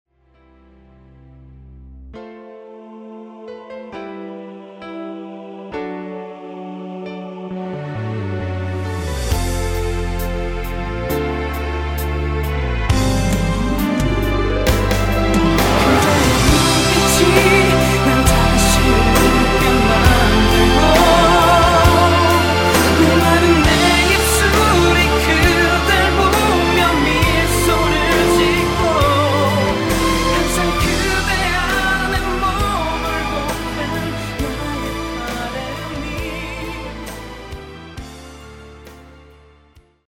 뒷부분 코러스 포함된 MR입니다.
앞부분30초, 뒷부분30초씩 편집해서 올려 드리고 있습니다.
중간에 음이 끈어지고 다시 나오는 이유는